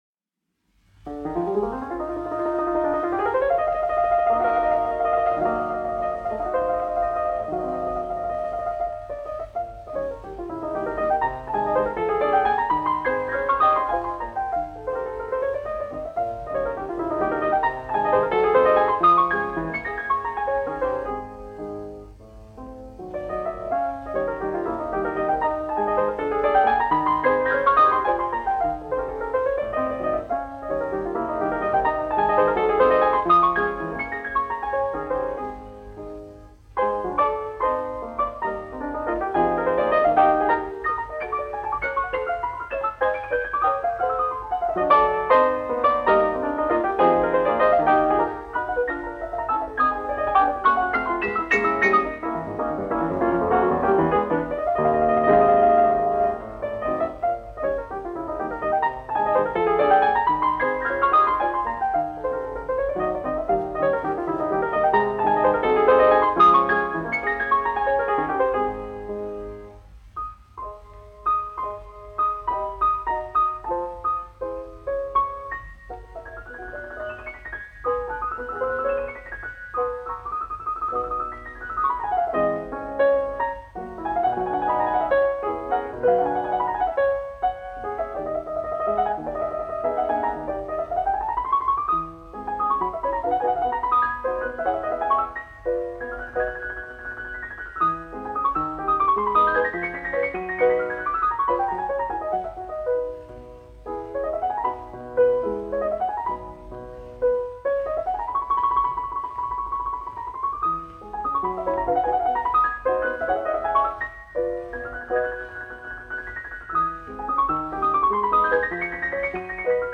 1 skpl. : analogs, 78 apgr/min, mono ; 25 cm
Klavieru mūzika
Skaņuplate